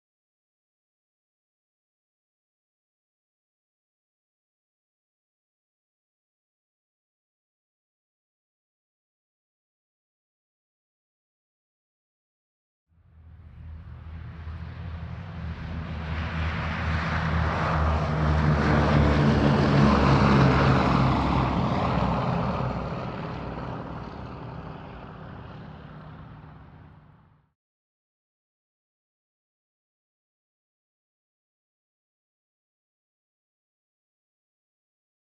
Boeing_B17_t2_Ext_Take_Off_By_CSS5.ogg